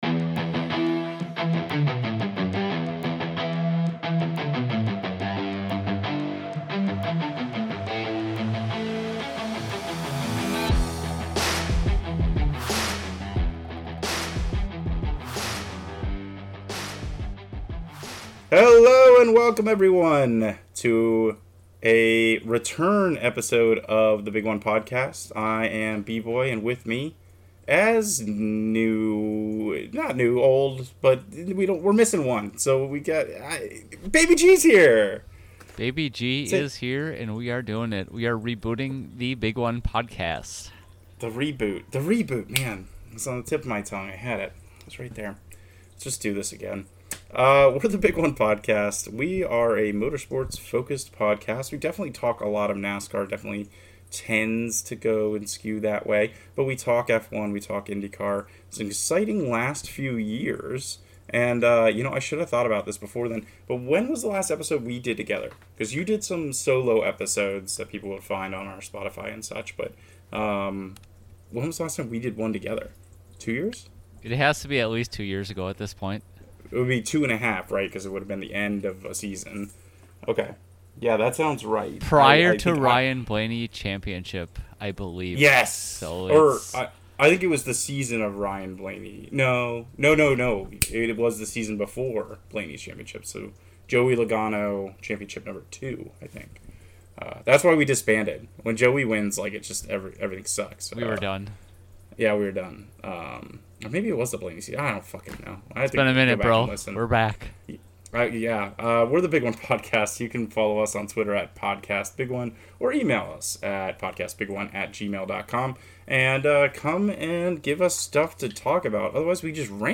A motorsports show by three buds that love racing. Each week we discuss Nascar's top 3 series - storylines, predictions, and the racing action as we've been seeing it as well as cover big storylines in F1, Formula E, Indycar, and local racing.